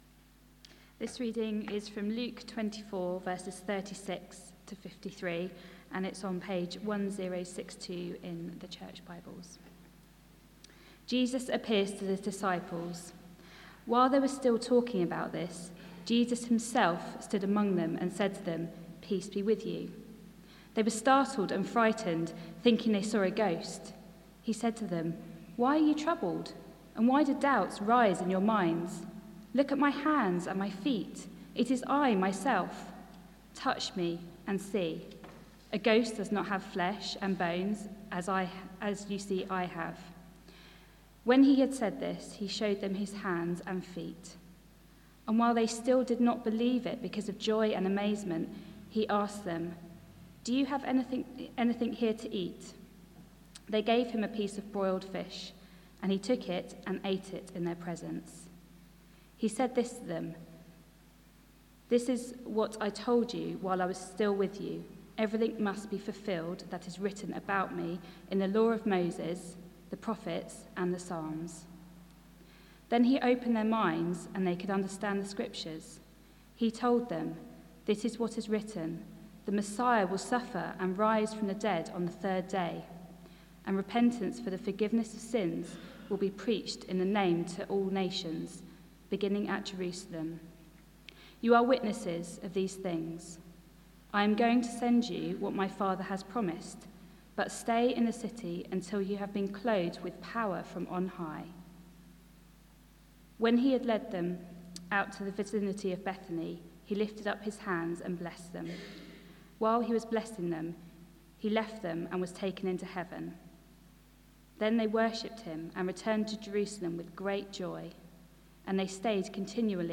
Bible Text: Luke 24: 36-53 | Preacher